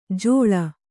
♪ jōḷa